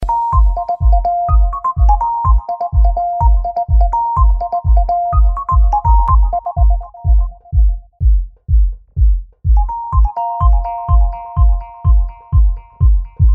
• Качество: 128, Stereo
low bass